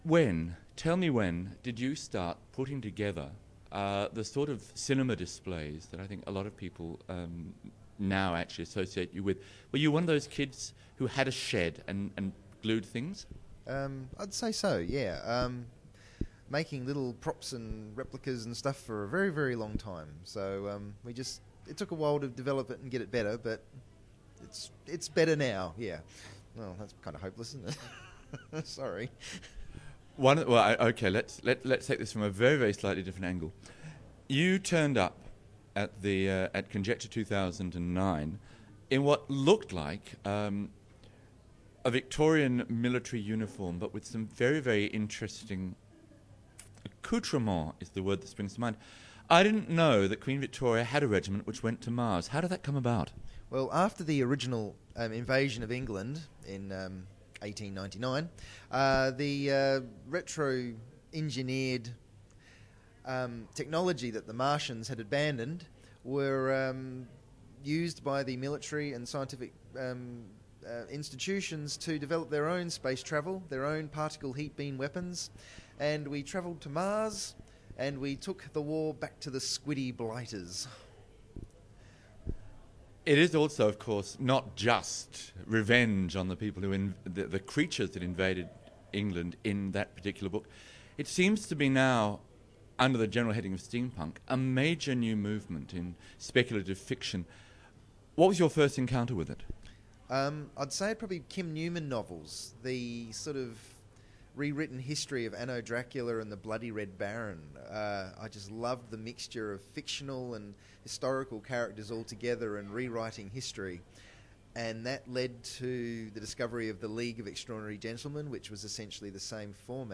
Conjecture Interviews – The Adelaide Fan Review